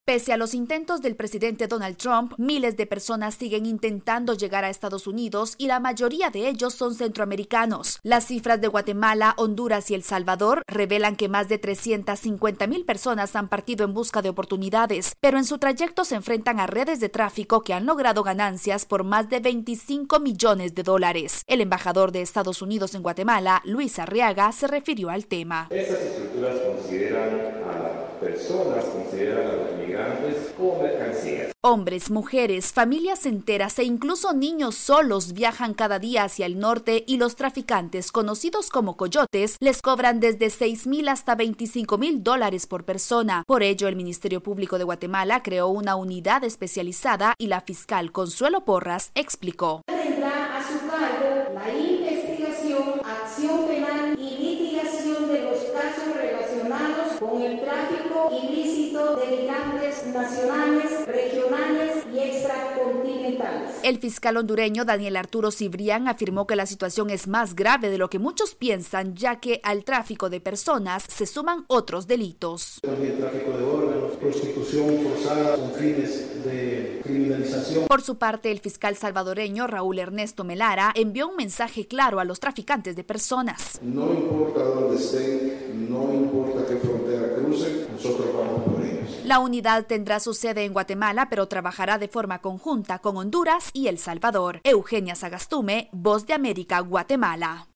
VOA: Informe desde Guatemala